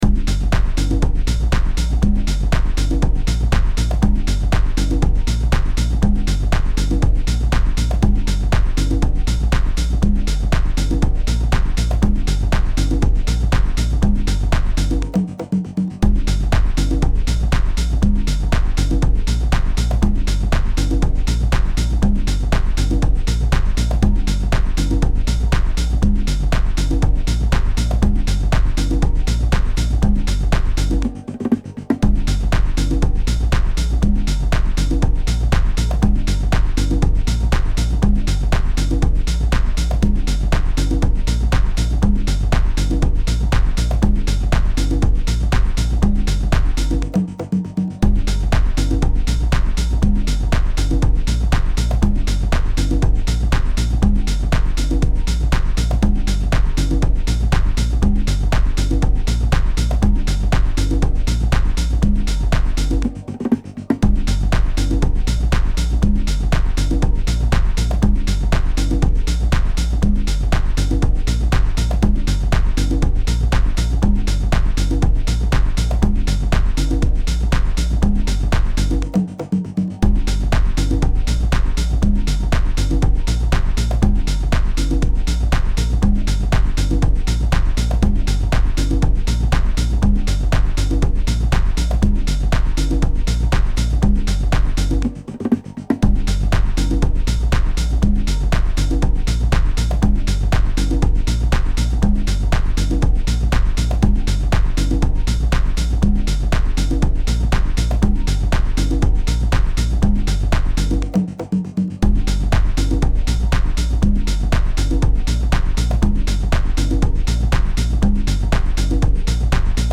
待機中などに使えそうなシンプルなループ曲です。